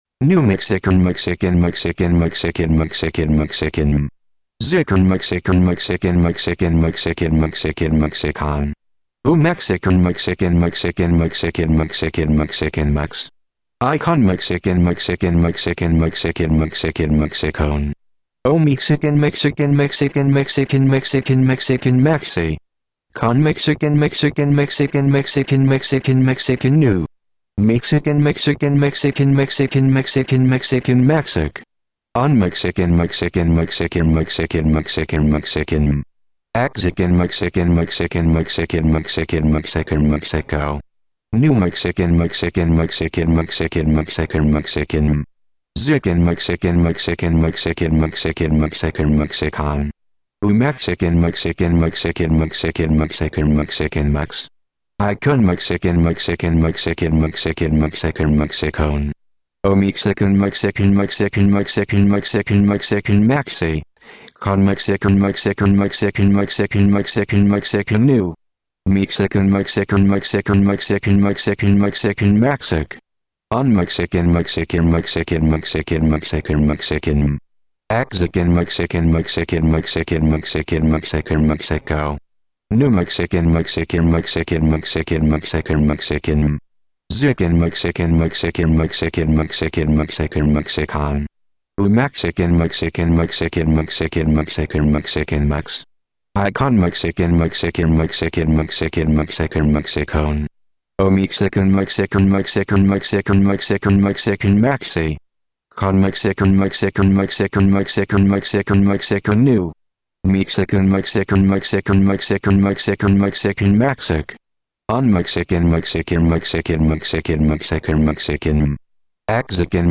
Dramatic reading